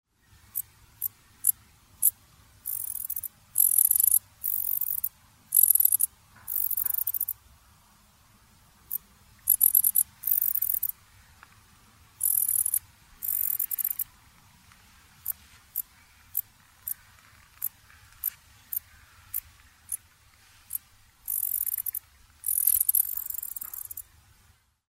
Dark Bush-cricket  Pholidoptera griseoaptera
Rainham Marshes foreshore, Essex, England  51° 29' 11.7" N   00° 13' 31.9" E  29 September 2018
Calls/stridulation from two male Dark Bush-crickets (Pholidoptera griseoaptera) during an aggressive encounter.
Usual single zirts (song) followed by rapid buzzes of up to 25 elements.